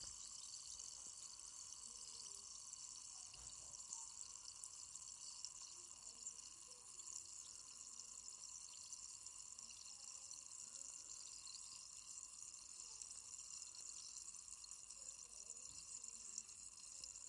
波光粼粼的水
描述：旋转塑料瓶用苏打水。 用Tascam DR05 V2录制
标签： 气泡 泡腾 飞溅 塑料 起泡 液体
声道立体声